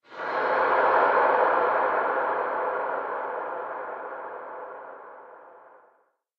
cave4.wav